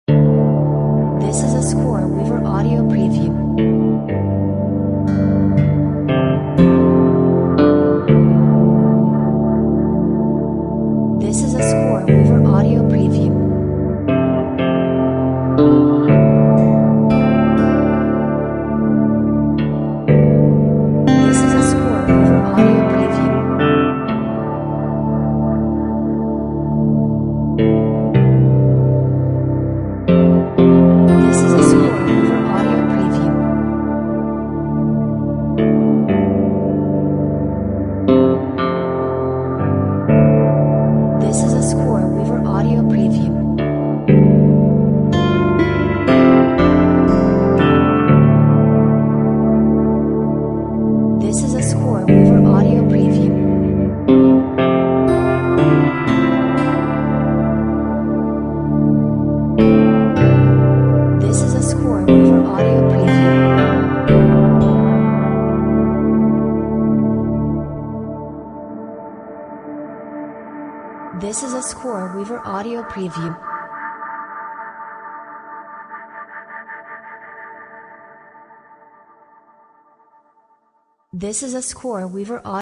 Melancholic post rock ambiance!